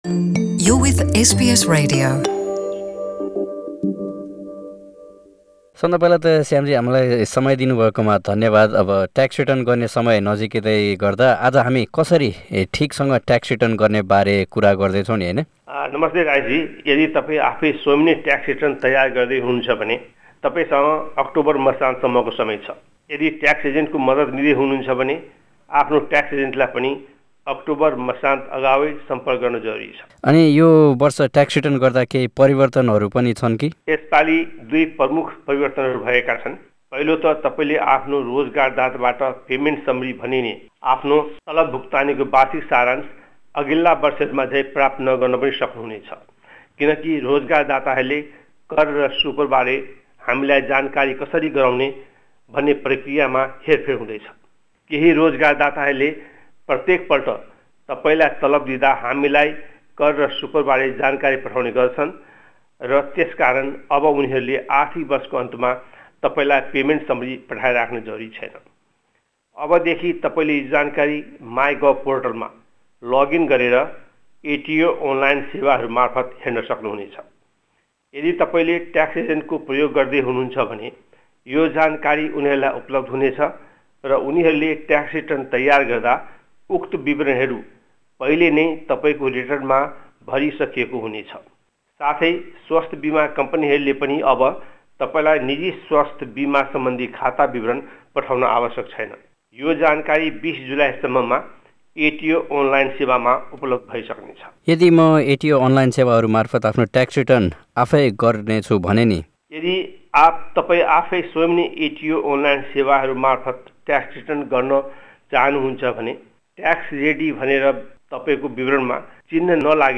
हाम्रो पुरा कुराकानी सुन्न माथि रहेको मिडिया प्लेयरमा प्ले बटन थिच्नुहोस् आज उहाँले हामीलाई यी कुराहरू बुझाउनु हुनेछ: यो वर्ष ट्याक्स रिटर्न गर्दा केही परिवर्तनहरू छन् कि? यदि म अस्ट्रेलियन कर कार्यालयका अनलाइन सेवाहरू मार्फत आफ्नो ट्याक्स रिटर्न आफैँ गर्छु भने के-कस्ता परिवर्तनहरु छन् त?